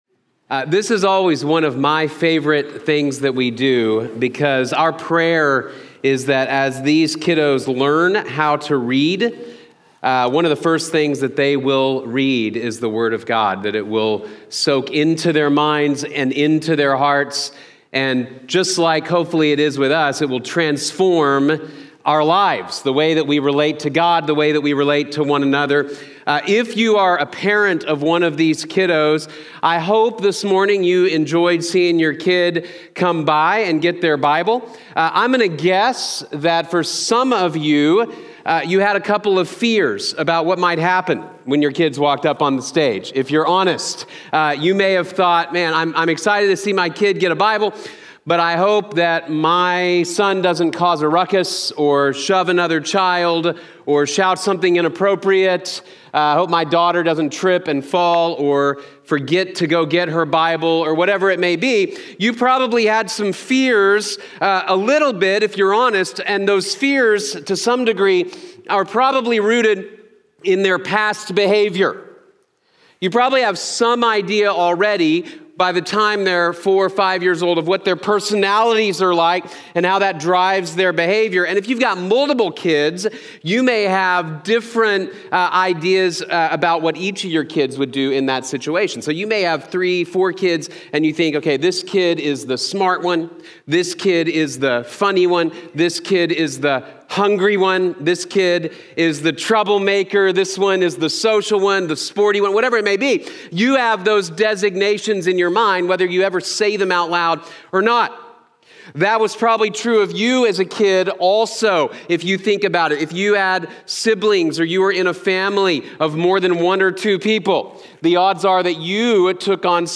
Children of God | Sermon | Grace Bible Church